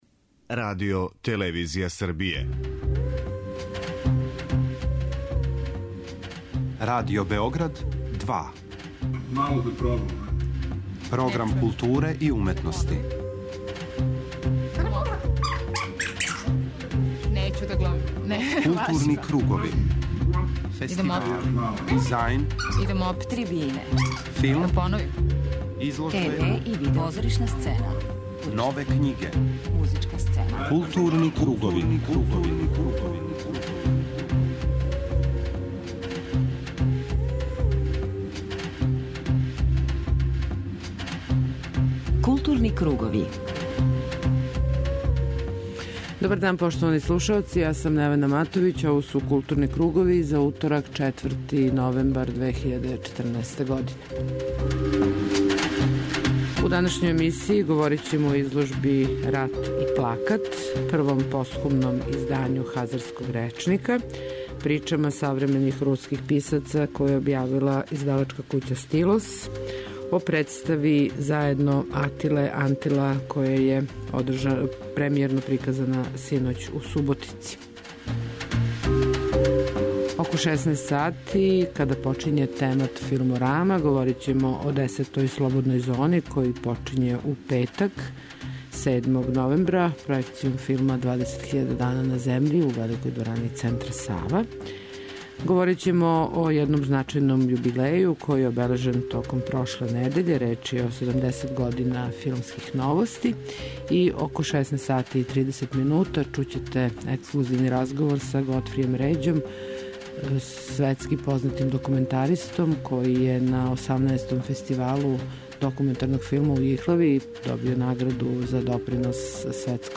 Следи рубрика Крупни план и ексклузивни интервју са редитељем Годфријем Ређом, снимљен током 18. Фестивала докуметарног филма у Јихлави, где је овој легенди светског докуметарног филма уричена Награда за допринос светској кинематографији .